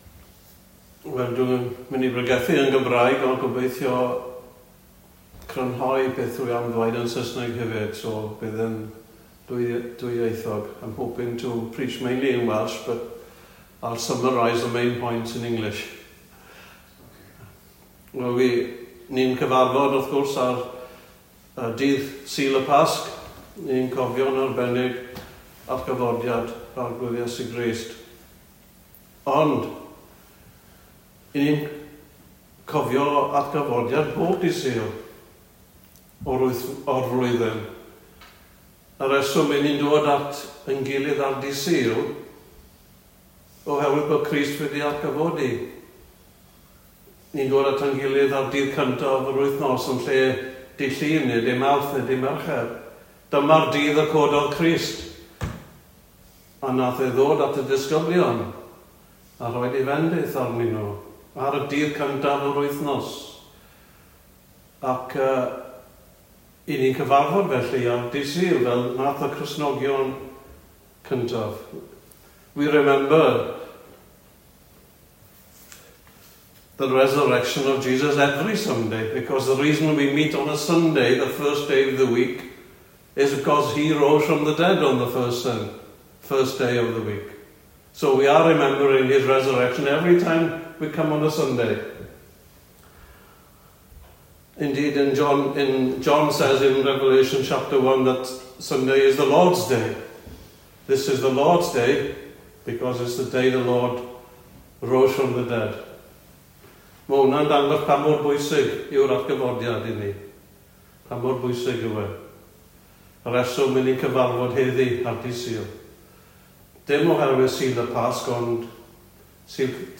Recorded Sermons preached at Rhos